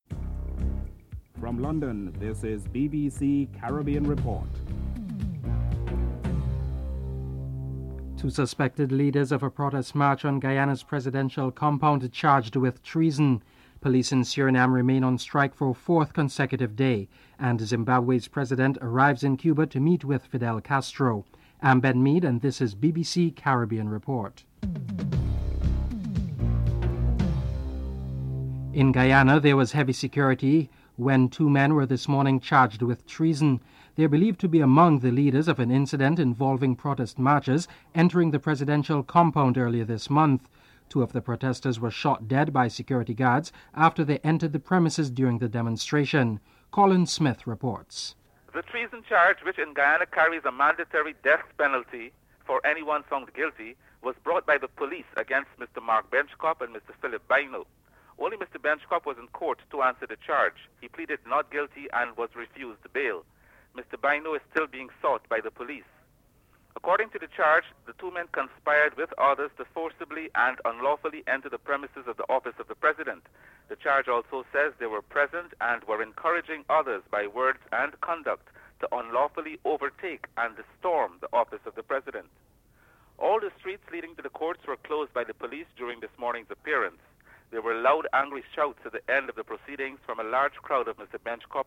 dc.description.tableofcontents1. Headlines (00:00-00:28)en_US